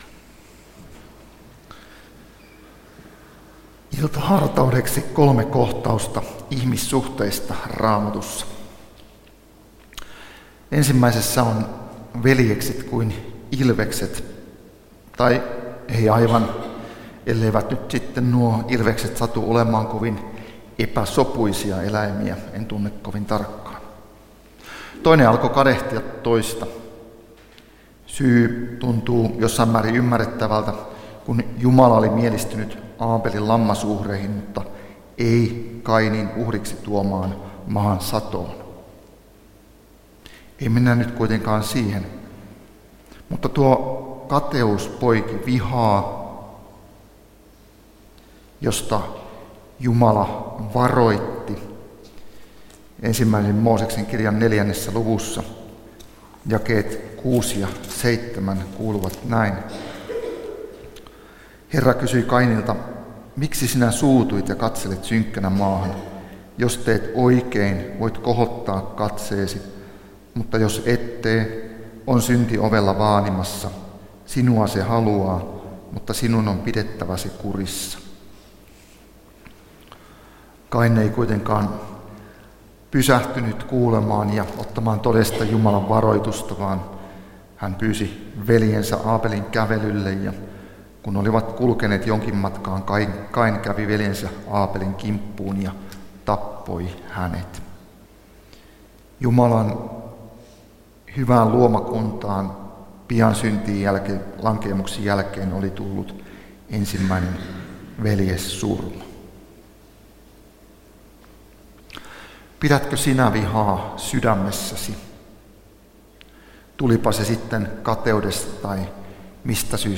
Kokoelmat: Ihmeellinen viikko Tampereella 2018